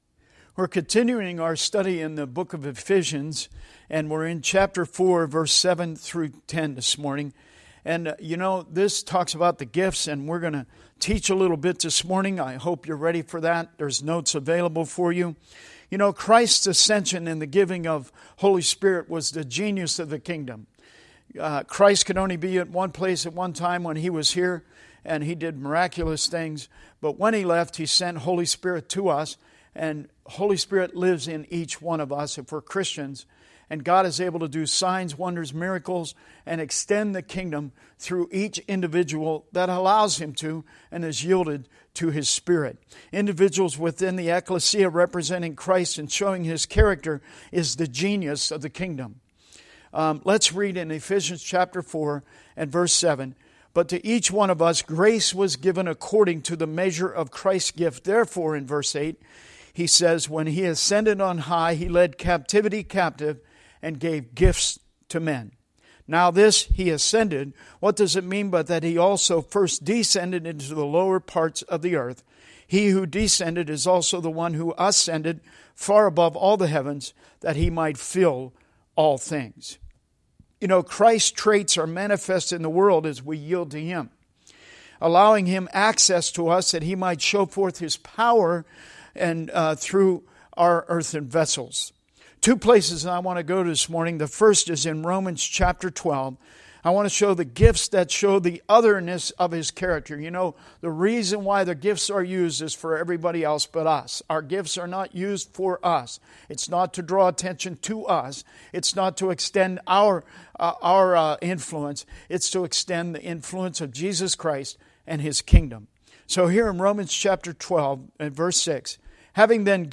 We’re currently in a series going through the book of Ephesians, listen to an interactive discussion on this week’s portion of Ephesians 4:7-10.
Service Type: Sunday Teaching